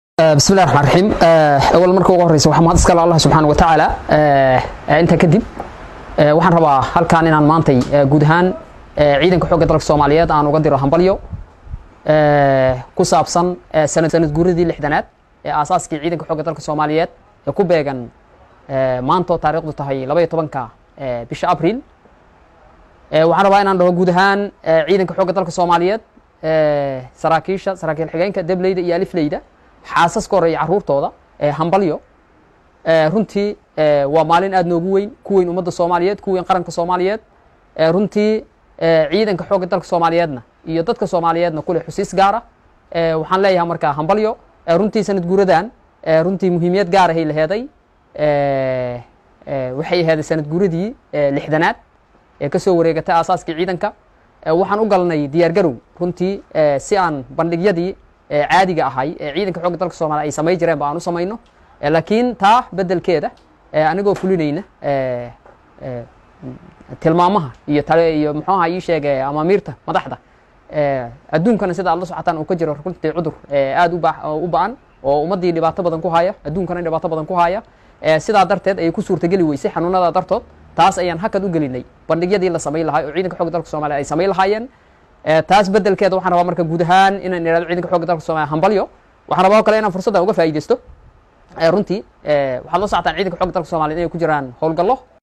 Halkaan ka dhageyso Codka Taliyaha Ciidanka Xooga Dalka Sareeyo guuto Odawaa Yuusuf Raage
COD-TALIYAHA-CIIDANKA-XOOGA-DALKA.mp3